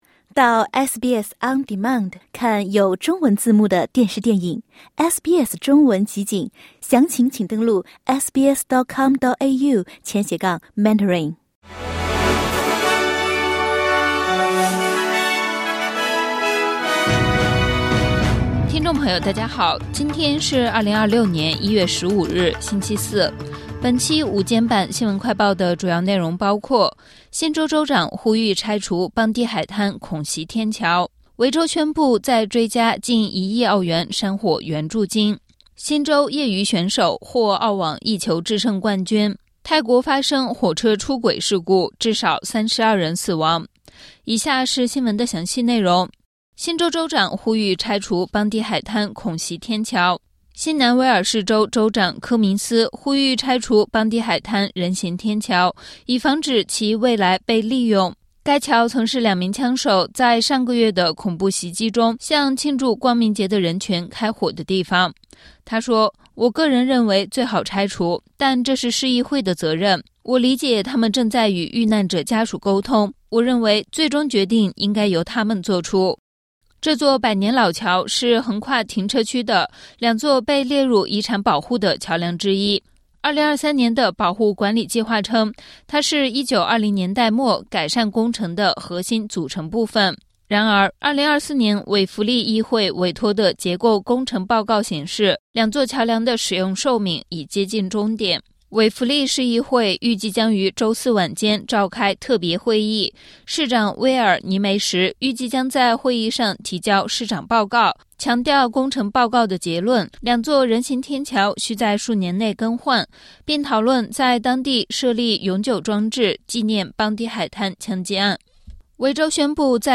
【SBS新闻快报】邦迪海滩人行天桥命运未卜 新州州长建议拆除